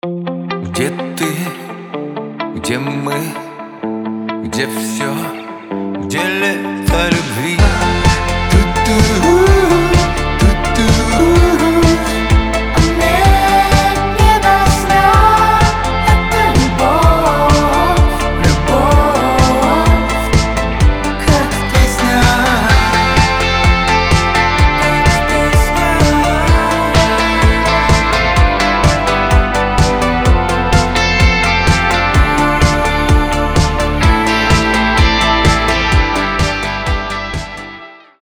• Качество: 320, Stereo
мужской голос
мелодичные